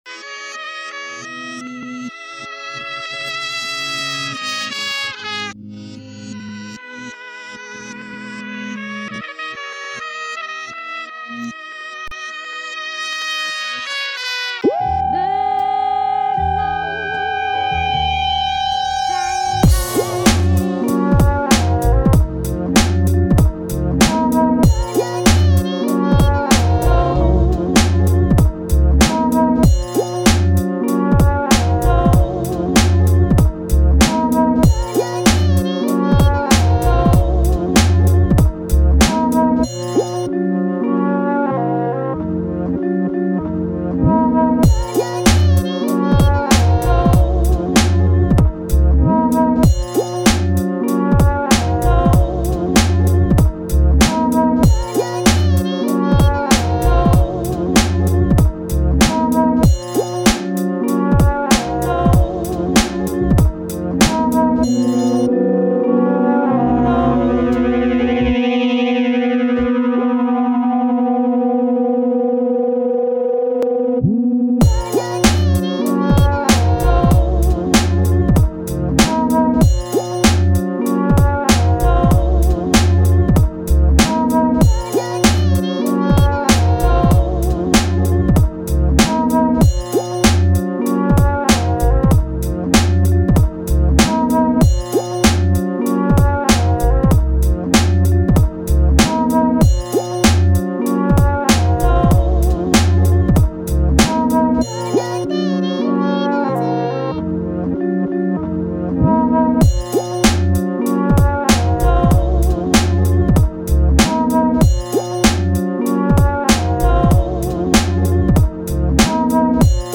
Категория: Instrumentals